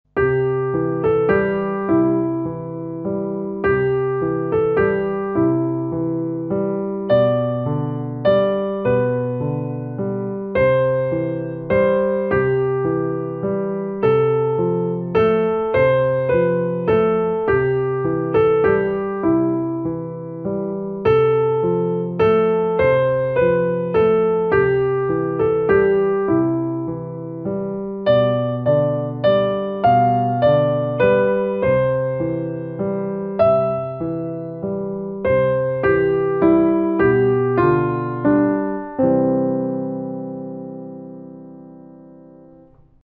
Lekce zaměřená na vánoční koledu Tichá noc (Silent night) v lehčí verzi - akordy nebo rozkládaný doprovod.
Ticha-noc-zacatecnici-vysledek-rozlozene-akordy.mp3